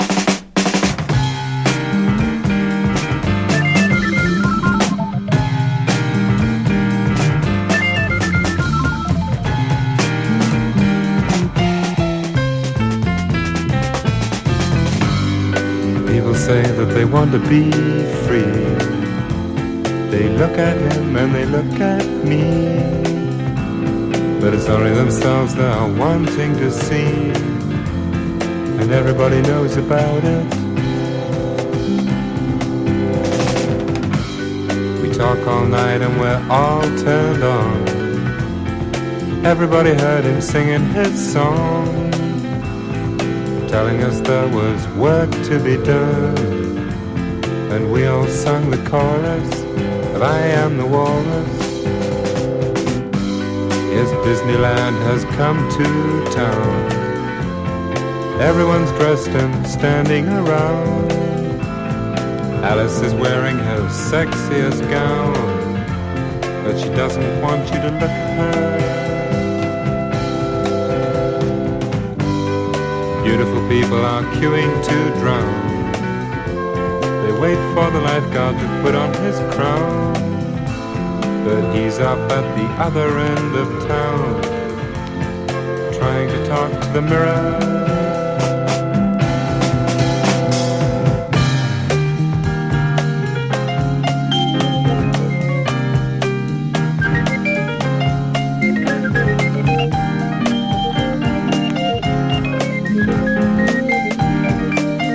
マジカルなカンタベリー・ロック/屈折サイケデリック・ポップ最高峰！